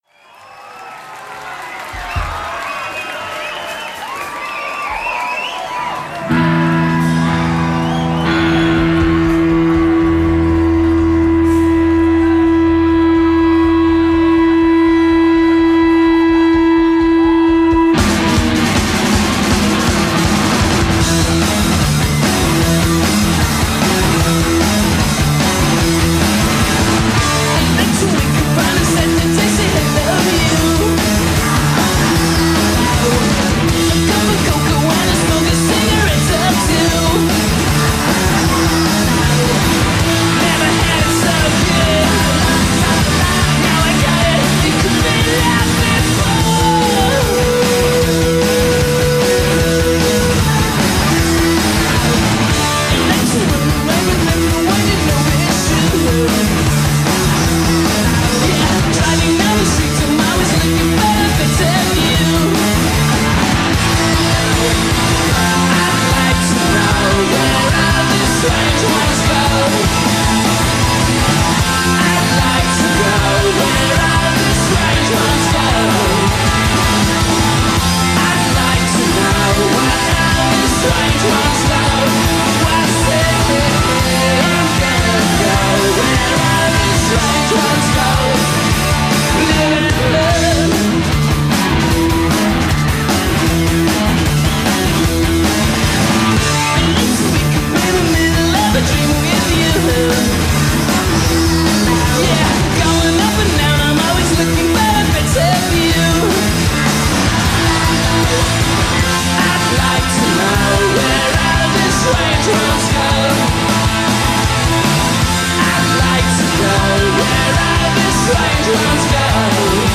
Recorded live at Rock City, Nottingham